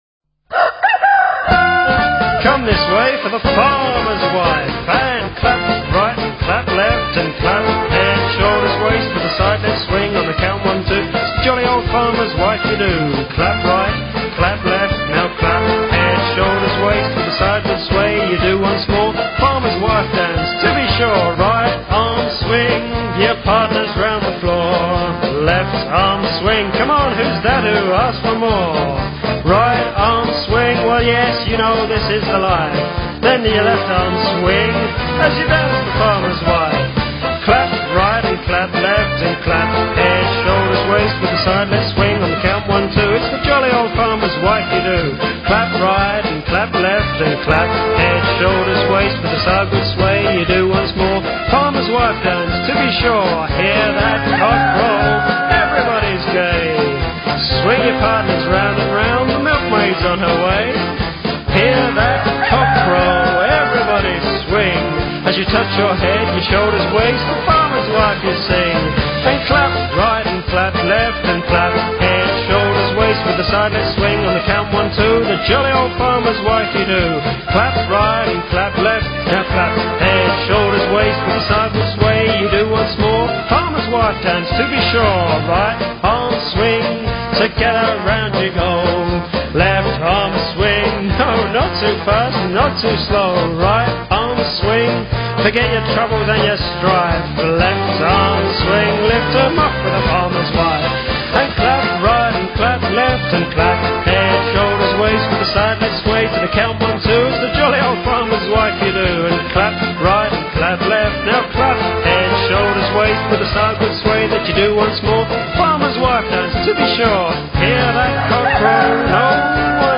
02-暖身操-曲目-農夫之妻-.WMA (另開視窗)
02-暖身操-曲目-農夫之妻-.wma